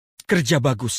Kategori: Suara viral
Keterangan: Efek suara "Kerja Bagus FF" viral di kalangan editor video dan meme Free Fire.